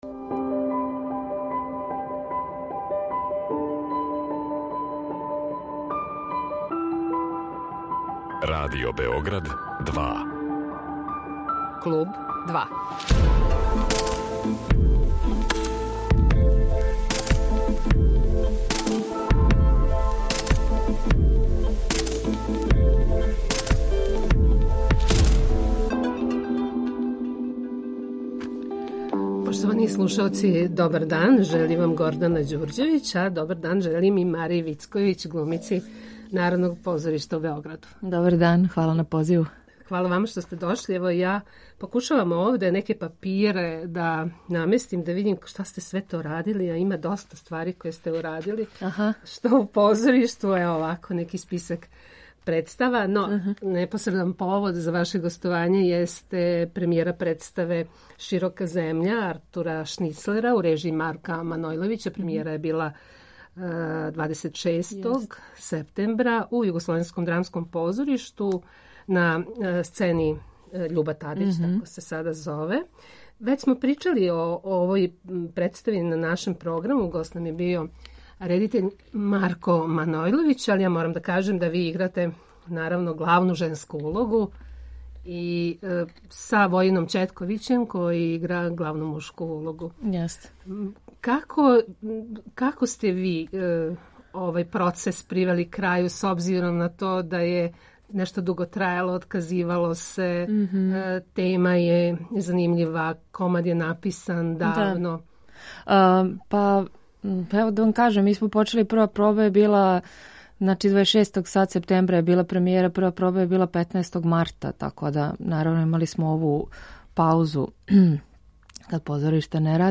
Гост Kлуба 2 је позоришна, филмска и телевизијска глумица